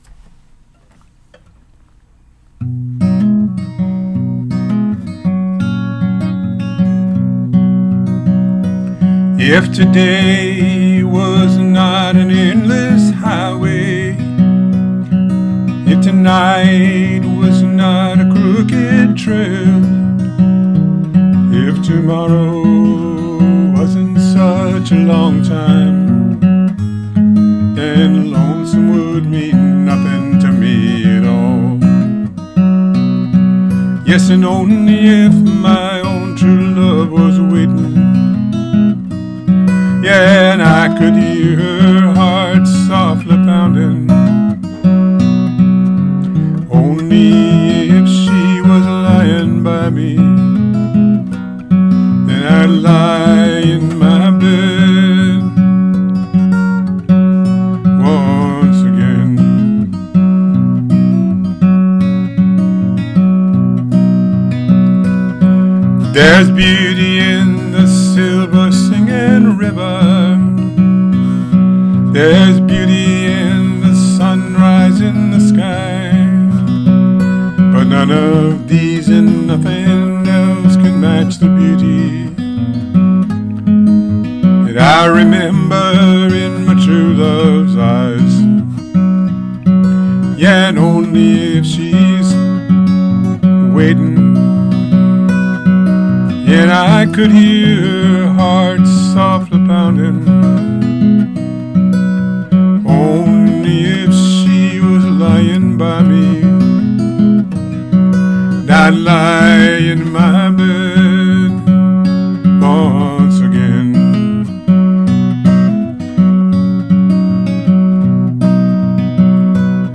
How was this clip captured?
Here’s my cover.